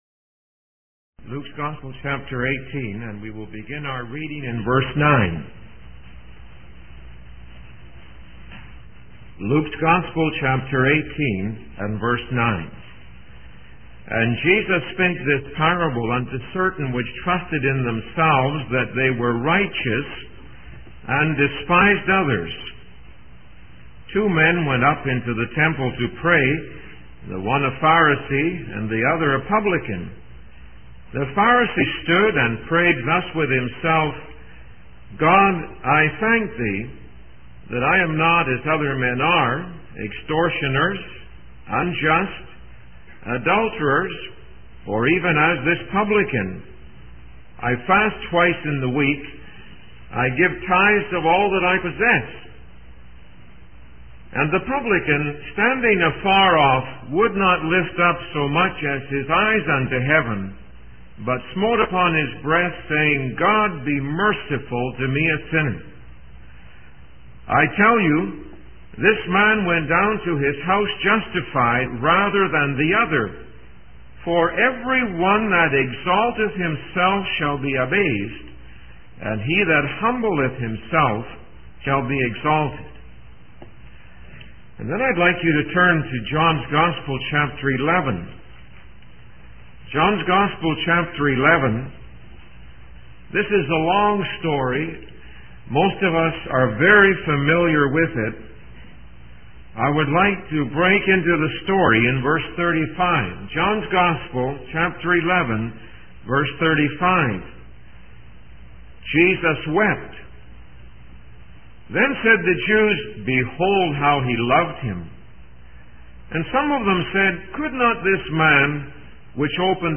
In this sermon, the speaker emphasizes the importance of gratitude and thanksgiving, particularly in the face of adversity and lack. He uses examples from Latin America, where many people struggle to find their next meal, to highlight the need for humility and compassion towards those less fortunate.